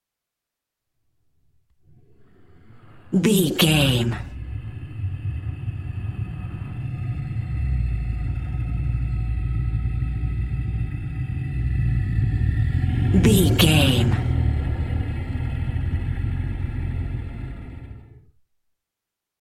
Wind evil slow growl air
Sound Effects
Atonal
scary
ominous
eerie